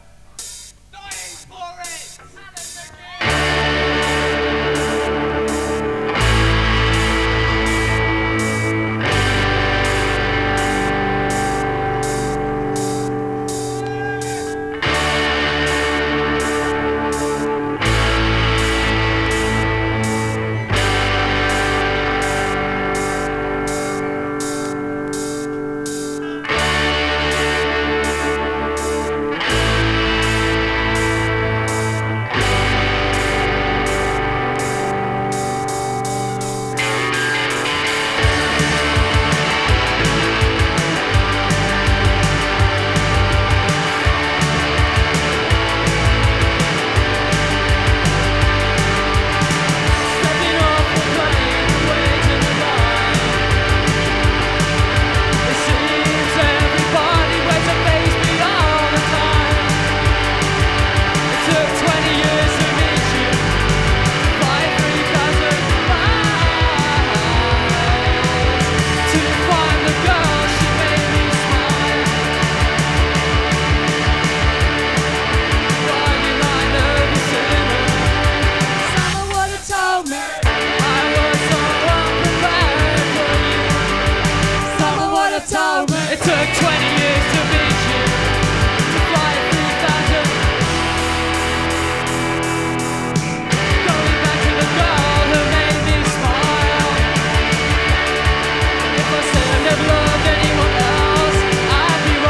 インディーポップ
通算では4作目にあたるライヴ盤。
[7track LP]＊時折パチ・ノイズ。